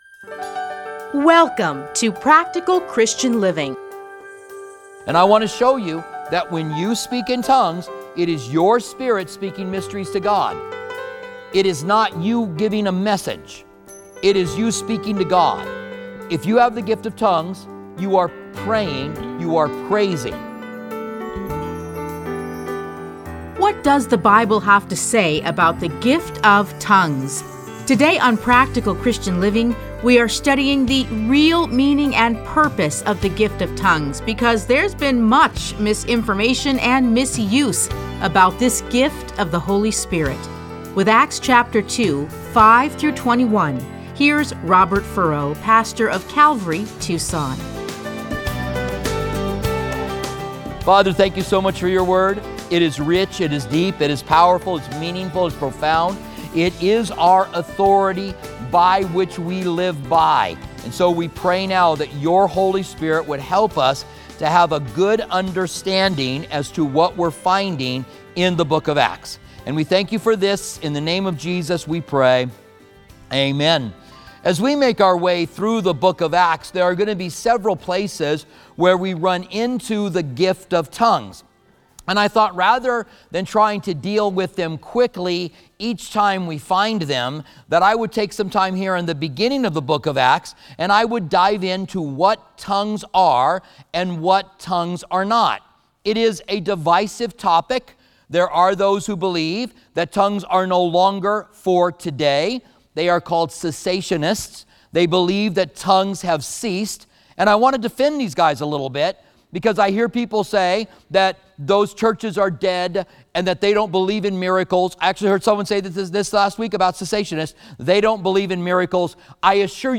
Listen to a teaching from Acts 2:5-21.